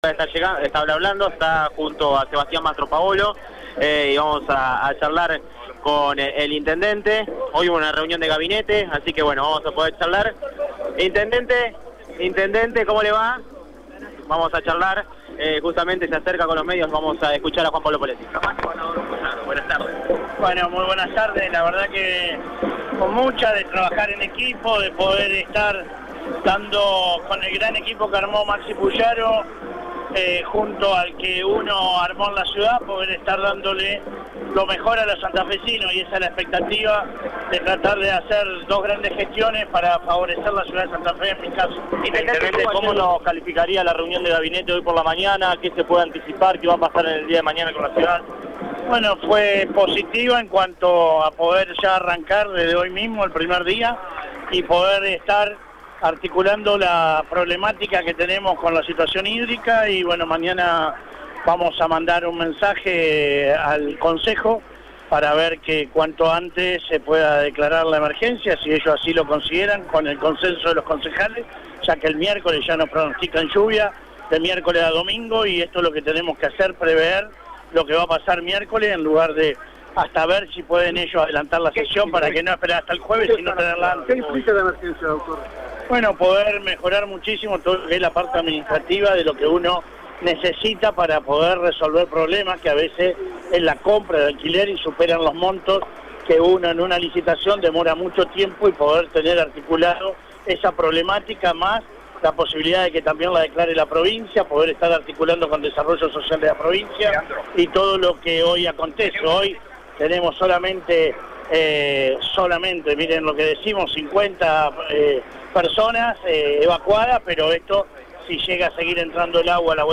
En el ingreso a la Legislatura santafesina, Radio EME está presente con su móvil para tomar contacto con los principales políticos en la asunción de Maximiliano Pullaro como Gobernador de la Provincia de Santa Fe.
Escucha la palabra de Juan Pablo Poletti en Radio EME: